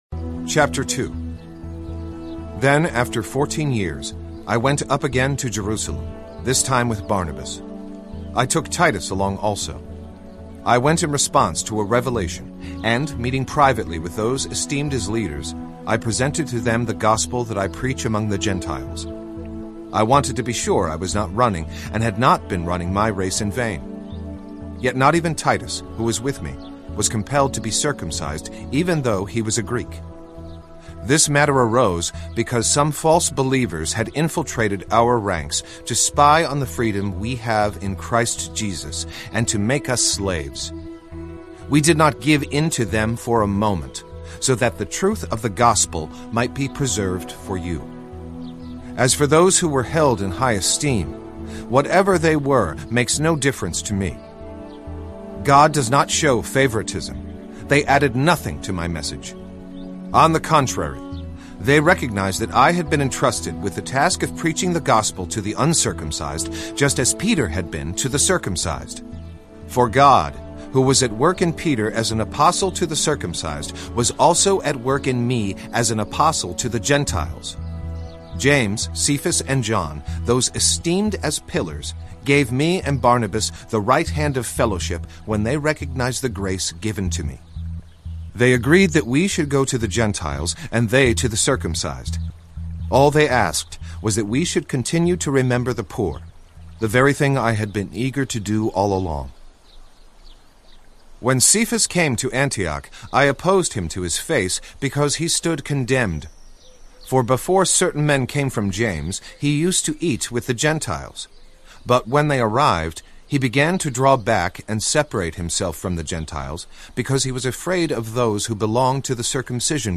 This dramatized audio version of Galatians, Ephesians, Philippians, and Colossians in the NIV brings the Bible to life with this true-to-text dramatization of God’s Word. The fully orchestrated background enriches the text reading and enhances the total experience.
Full Cast
Dramatized Audio Bible Galatians ZV Sample.mp3